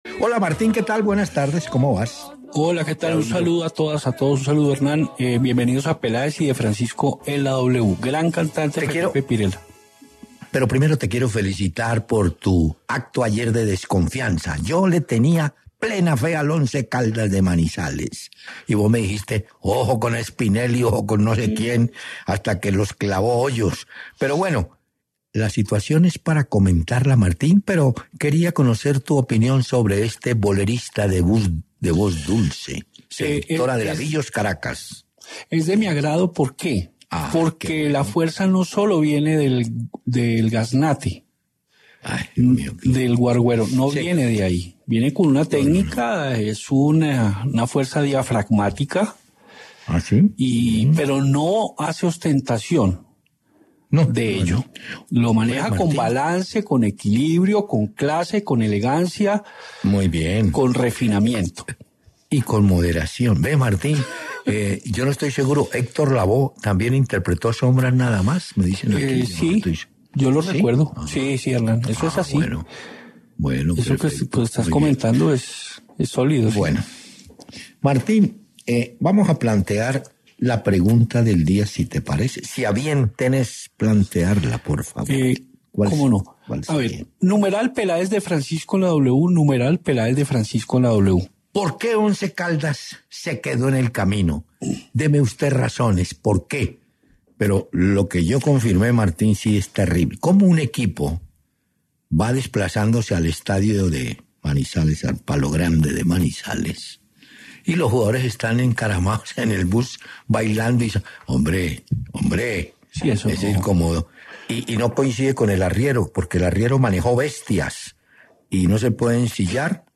Hernán Peláez y Martín de Francisco conversaron sobre la derrota del Once Caldas ante Independiente del Valle y su eliminación de la Sudamericana.